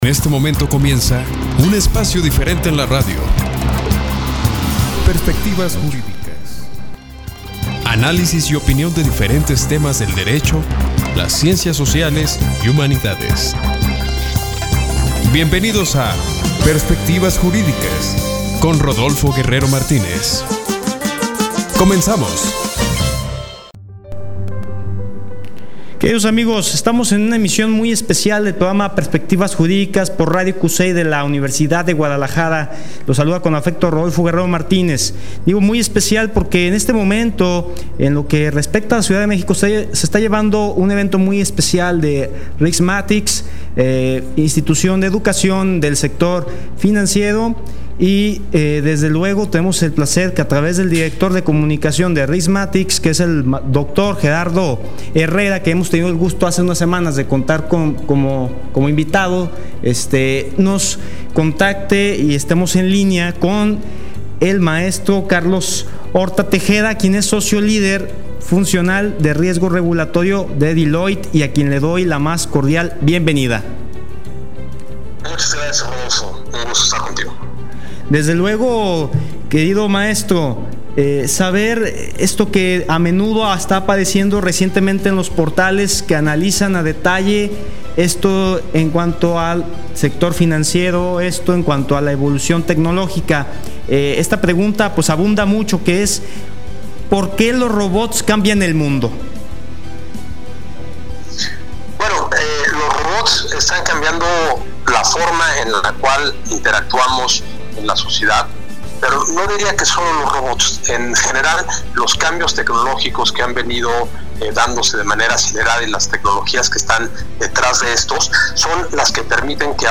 En entrevista para Perspectivas Jurídicas